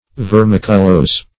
Search Result for " vermiculose" : The Collaborative International Dictionary of English v.0.48: Vermiculose \Ver*mic"u*lose`\, Vermiculous \Ver*mic"u*lous\, a. [L. vermiculosus.